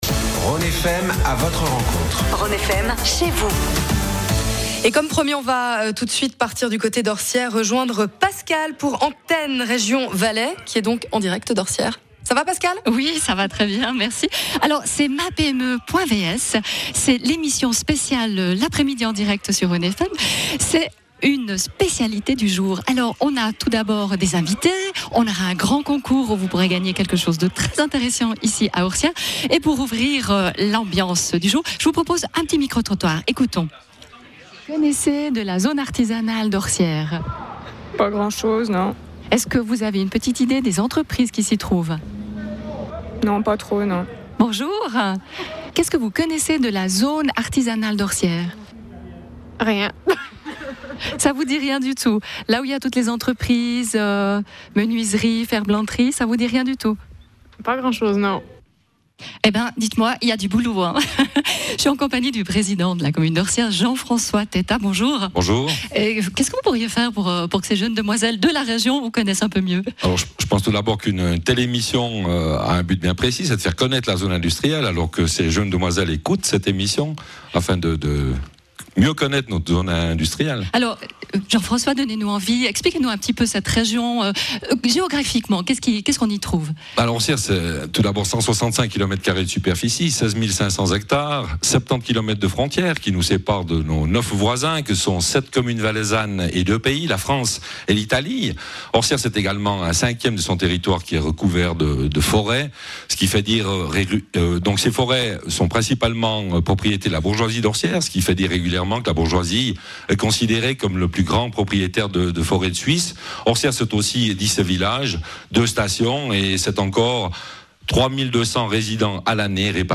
Après une présentation de sa zone industrielle par le Président de commune, les interviews en direct de plusieurs entrepreneurs vous permettront de mieux connaitre une commune et son économie !
Interview du Président de commune, M.